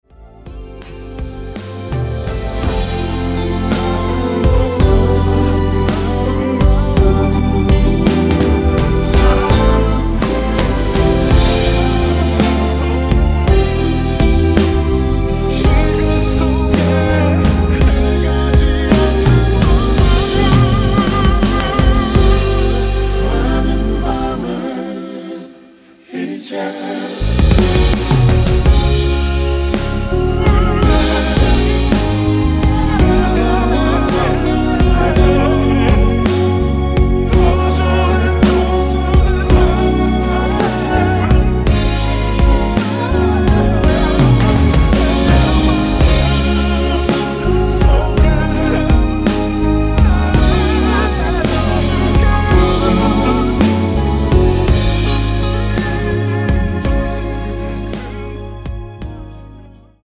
원키에서(-2)내린 코러스 포함된 MR입니다.(미리듣기 참조)
앞부분30초, 뒷부분30초씩 편집해서 올려 드리고 있습니다.
중간에 음이 끈어지고 다시 나오는 이유는